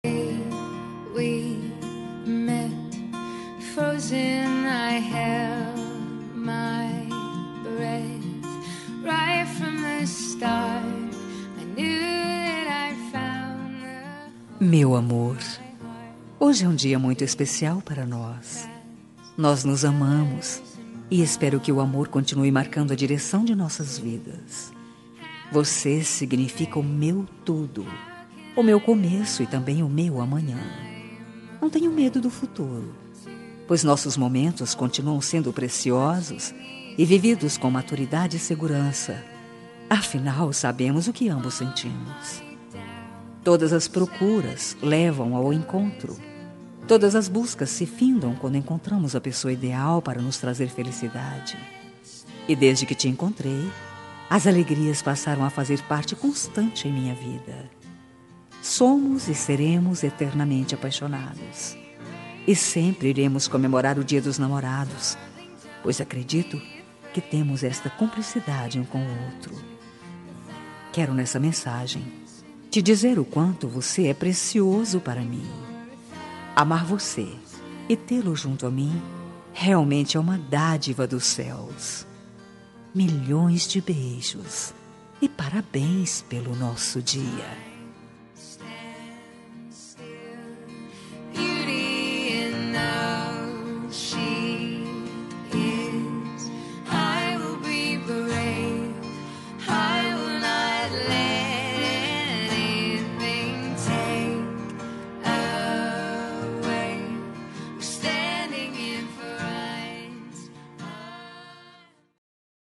Dia dos Namorados – Para Marido – Voz Feminina – Cód: 6891